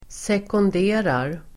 Ladda ner uttalet
Uttal: [sekund'e:rar]
sekonderar.mp3